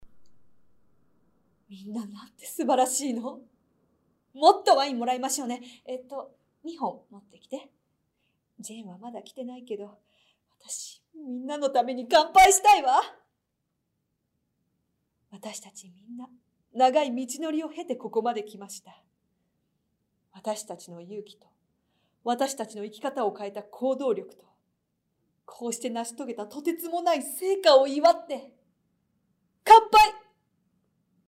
ボイスサンプルはこちら↓　キャリアウーマン
ボイスサンプル